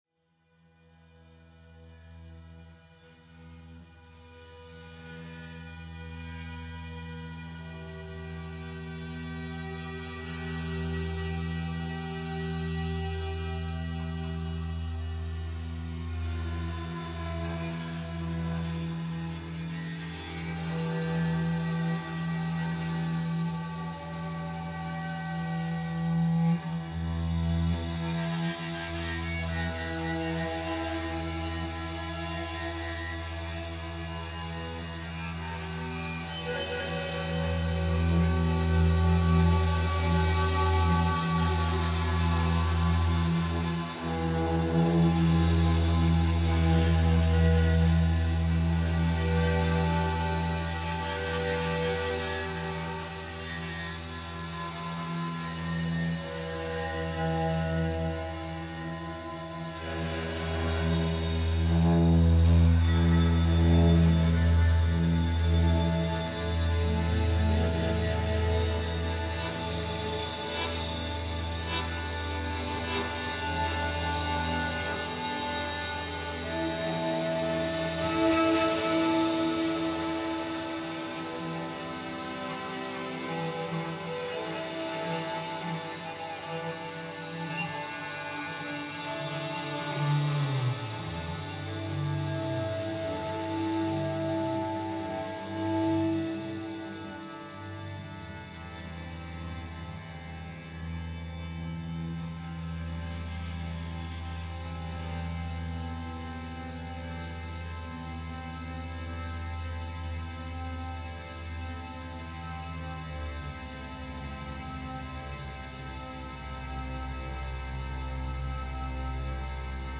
guitare électrique